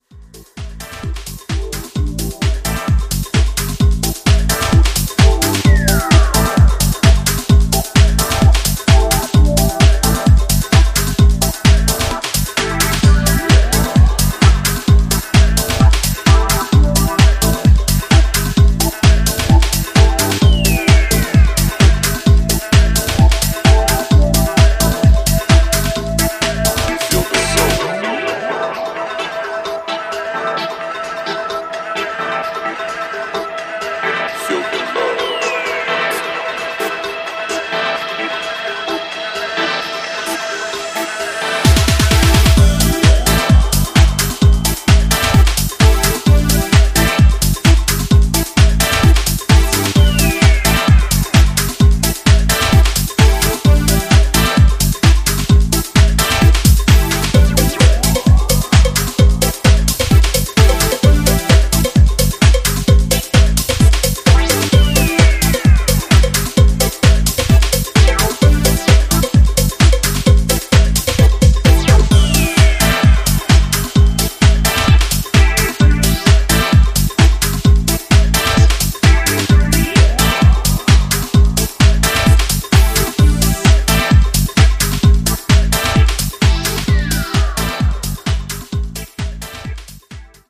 各曲フレッシュな勢いで溢れており、ピークタイムを気持ちよく沸かせてくれることでしょう！